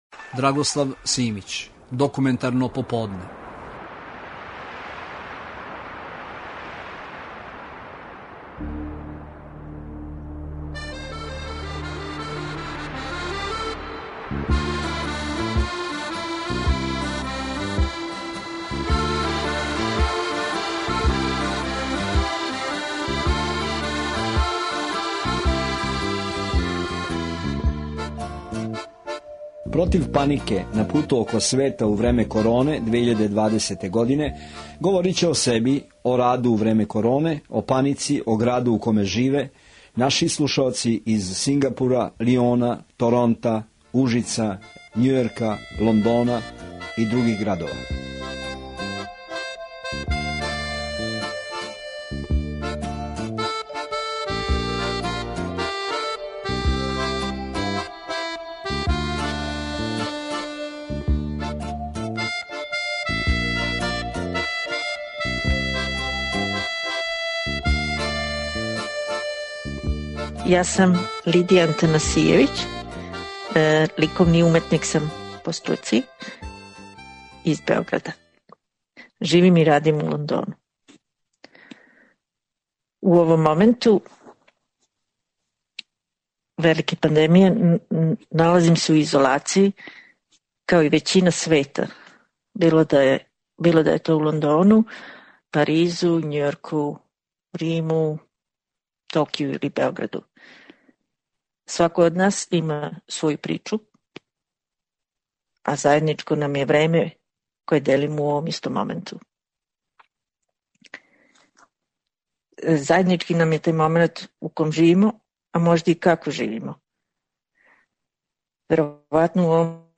Документарни програм
Овај тонски запис снимљен "око планете" док траје корона, начињен је делом те технологије, скајпом.